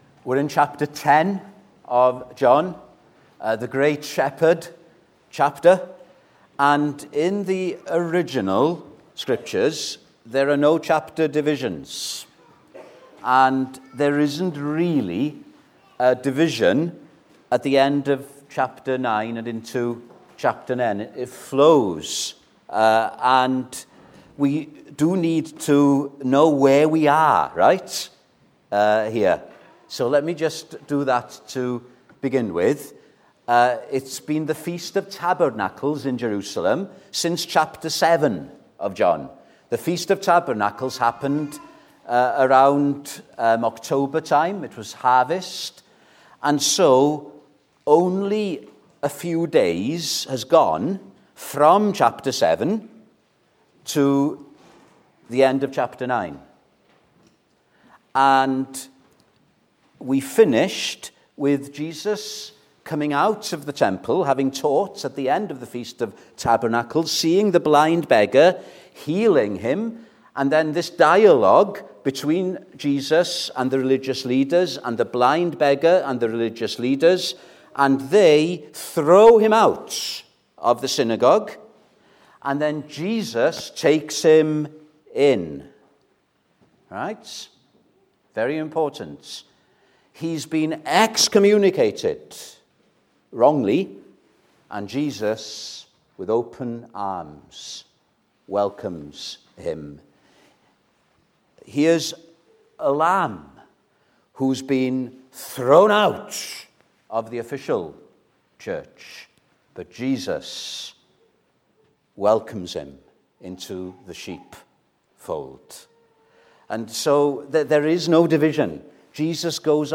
Recordings of the sermons from the morning services at Heath Evangelical Church, Cardiff.